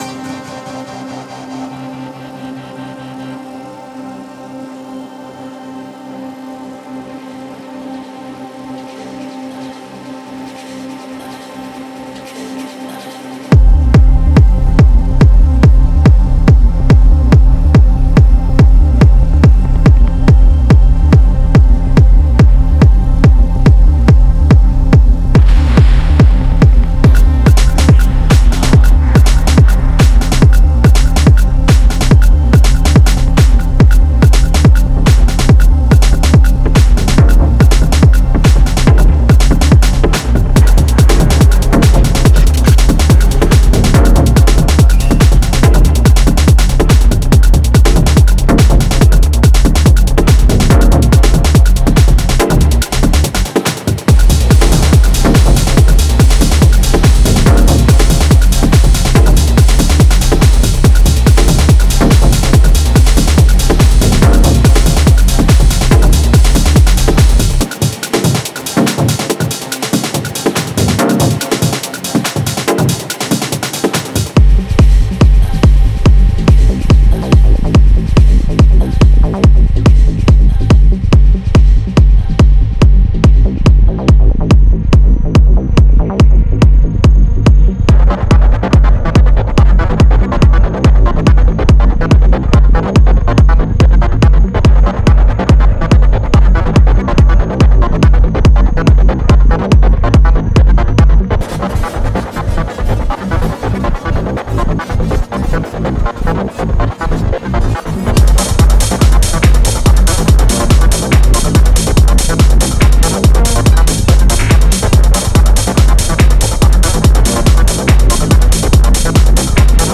Genre:Techno
これは真のテクノです――荒々しく、インダストリアルで、容赦なくダーク。
胸を打ち砕くブラーム、ダークFX、ねじれたシンセFX、インパクト、ライザー、不穏なシンセドローンまで網羅しています。
デモサウンドはコチラ↓
175 Analog Modular Synth Loops (Leads, Plucks, Basses)